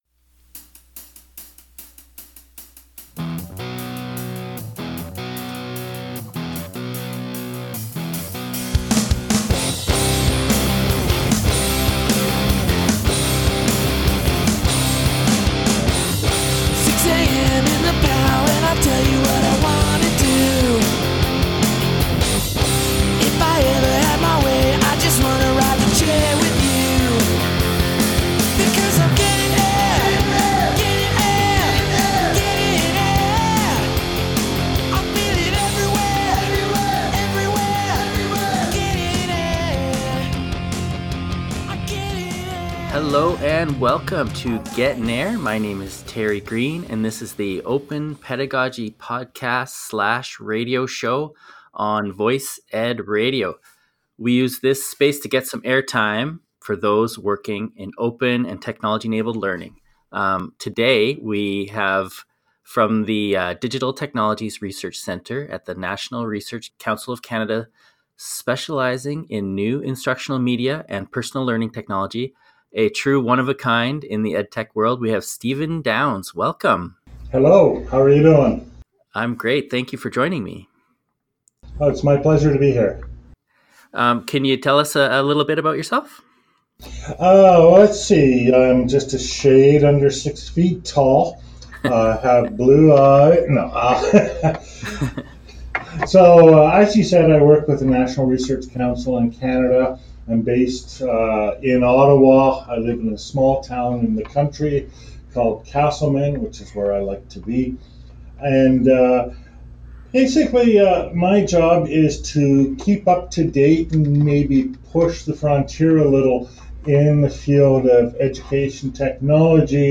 Gettin Air Previous Next Page: / Author: Downloads: (Old style) [ PDF ] [ Audio ] [] , Online, via Zencaster, Interview, Sept 13, 2019.